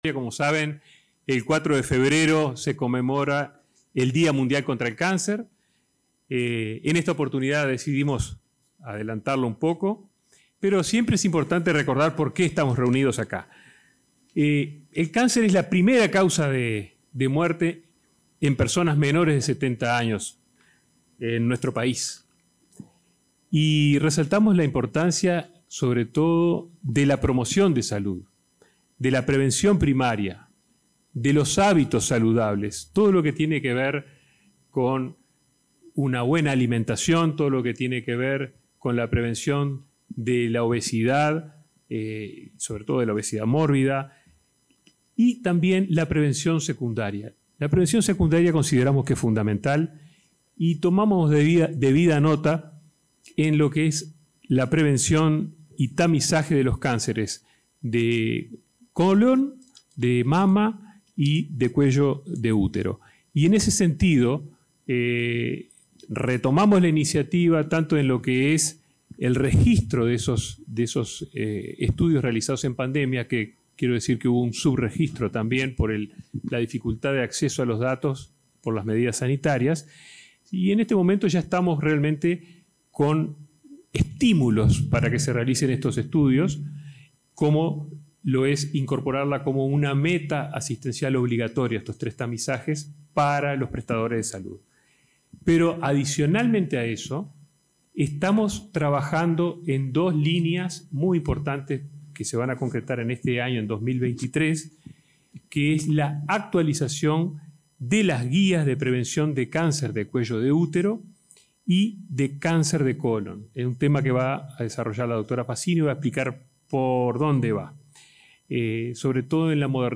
Palabras del ministro de Salud Pública, Daniel Salinas
Palabras del ministro de Salud Pública, Daniel Salinas 02/02/2023 Compartir Facebook X Copiar enlace WhatsApp LinkedIn En el marco del Día Mundial de Lucha Contra el Cáncer, este 2 de febrero, el ministro de Salud Pública, Daniel Salinas, realizó declaraciones en el acto.